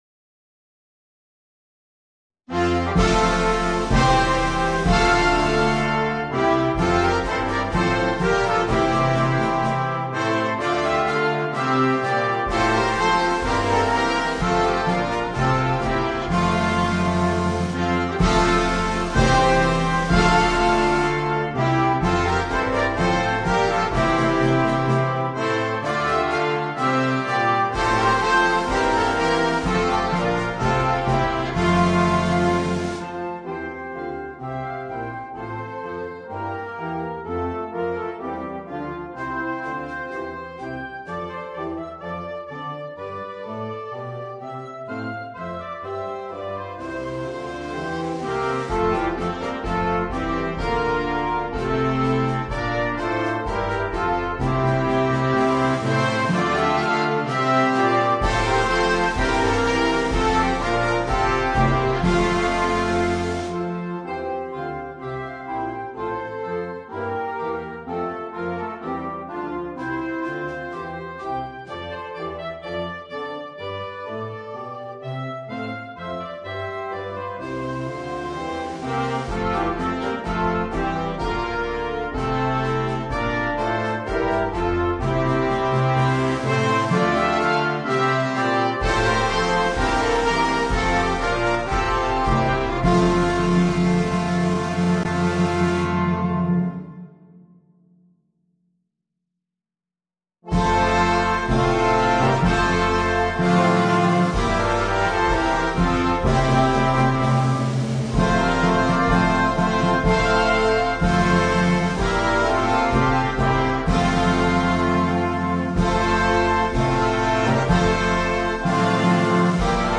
Questa raffinata trascrizione per banda